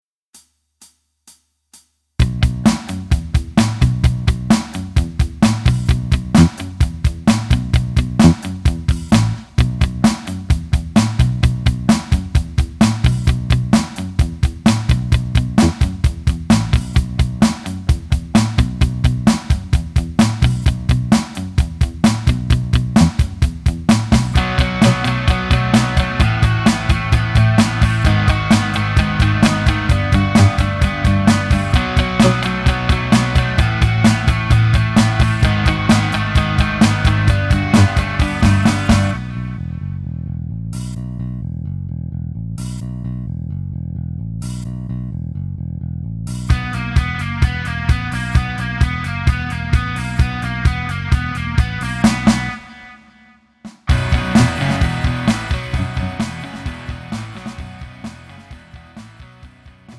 음정 남자키
장르 pop 구분 Pro MR